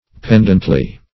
pendently - definition of pendently - synonyms, pronunciation, spelling from Free Dictionary Search Result for " pendently" : The Collaborative International Dictionary of English v.0.48: Pendently \Pend"ent*ly\, adv. In a pendent manner.